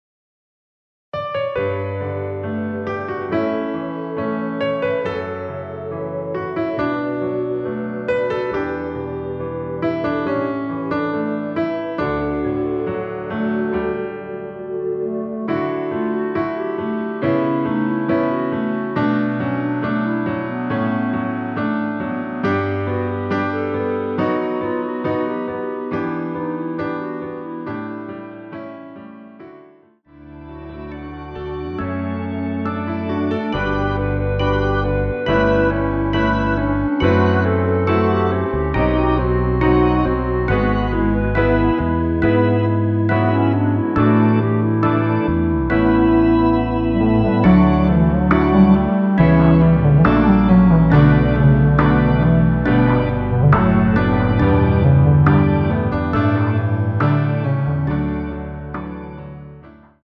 중간 간주 부분이 길어서 짧게 편곡 하였습니다.(미리듣기 확인)
원키 멜로디 포함된 간주 짧게 편곡한 MR입니다.
D
앞부분30초, 뒷부분30초씩 편집해서 올려 드리고 있습니다.
중간에 음이 끈어지고 다시 나오는 이유는